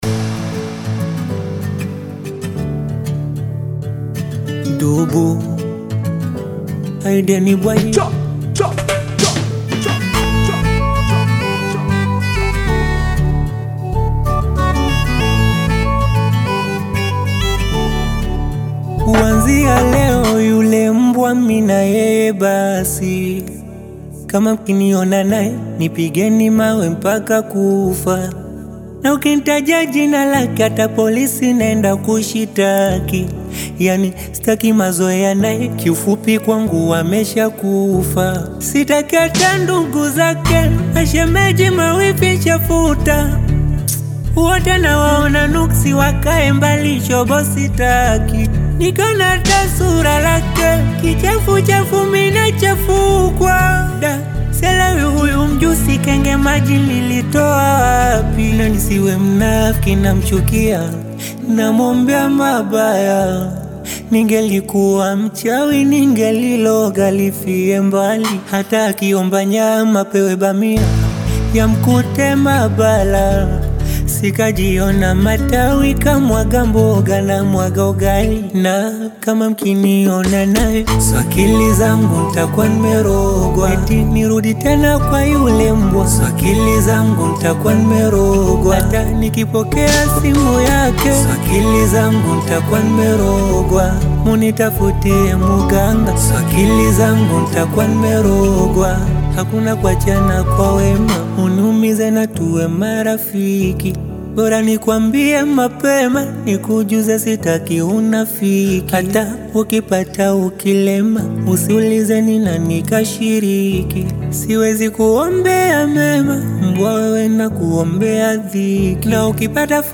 Bongo Flava music track
Tanzanian Bongo Flava artist